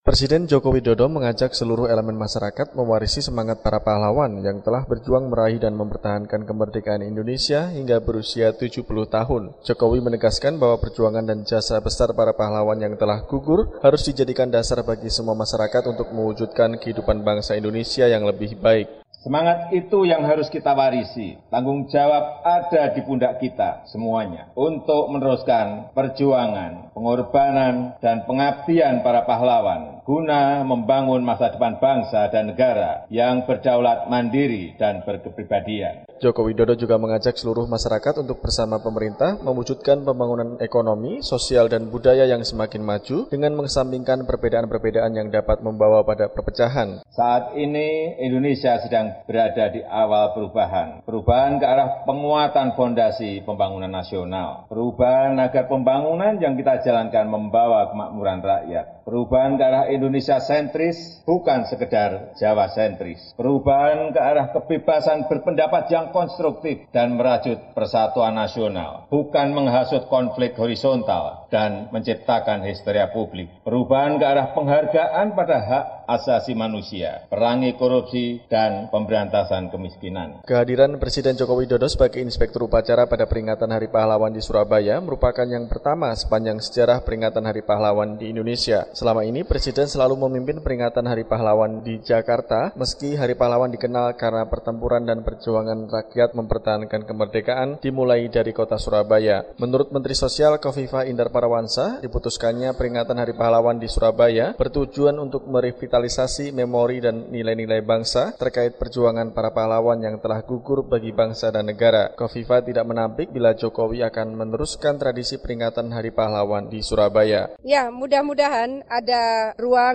Presiden Republik Indonesia Joko Widodo, memimpin upacara peringatan Hari Pahlawan 10 November, di halaman Monumen Tugu pahlawan di Surabaya.